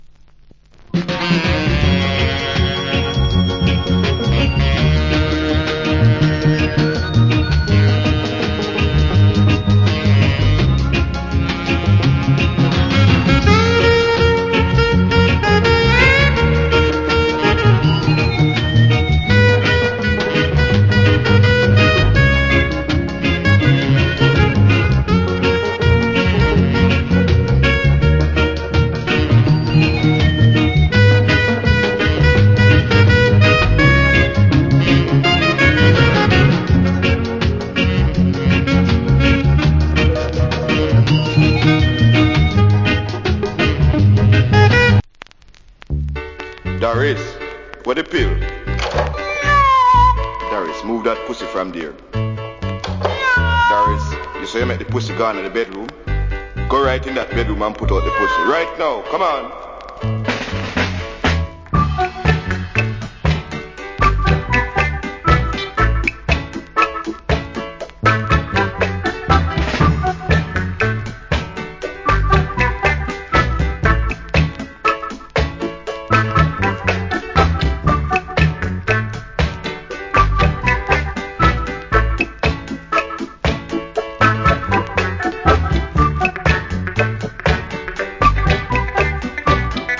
Nice Early Reggae Inst.